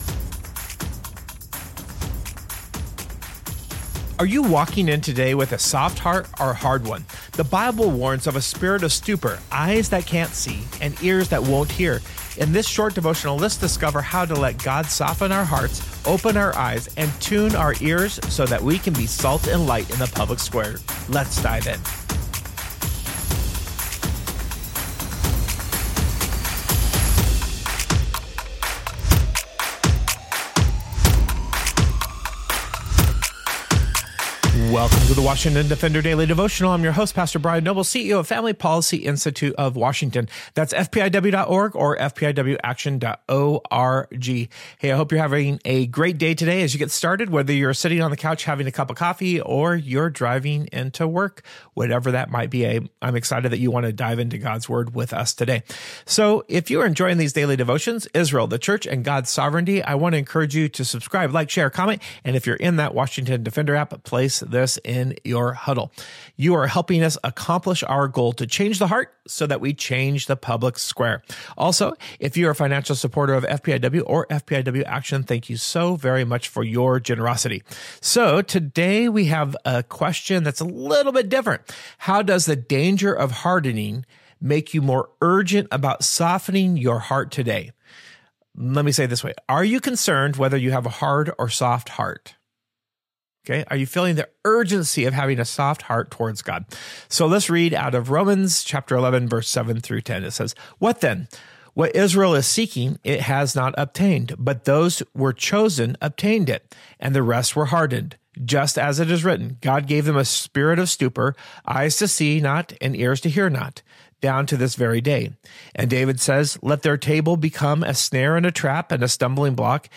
In this short devotional, discover how to let God soften your heart, open your eyes, and tune your ears so you can be salt and light in the public square today.”